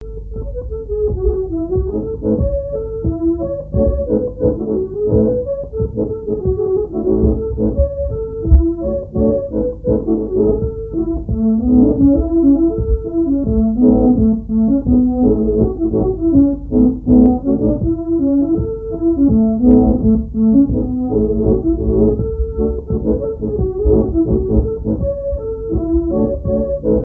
danse : scottich trois pas
Répertoire à l'accordéon diatonique
Pièce musicale inédite